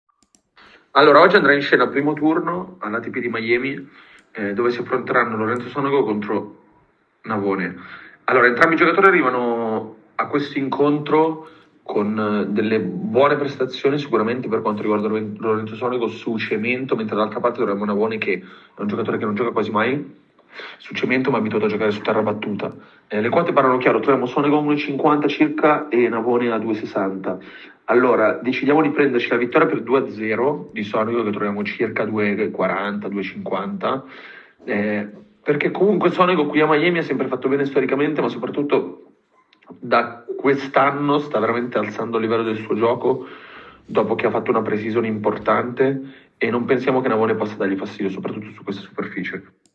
In questa veloce audio-analisi il nostro esperto di scommesse sul tennis